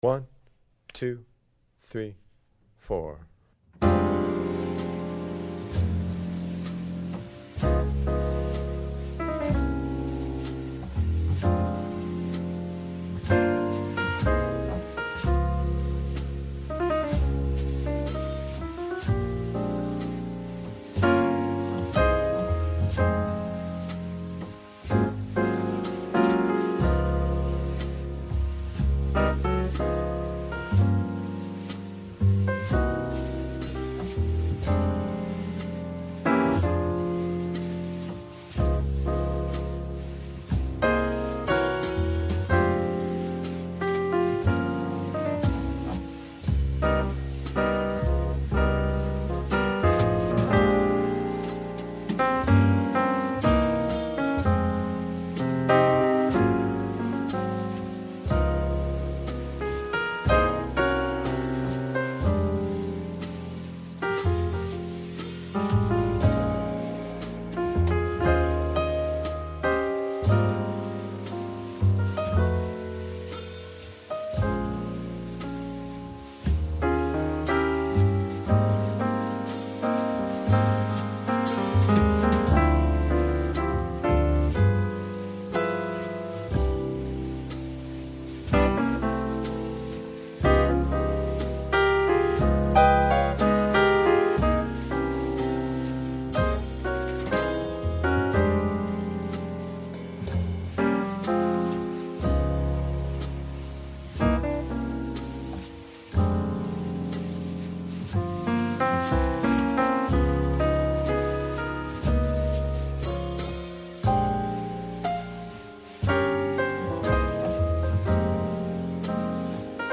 YDKWLI Backing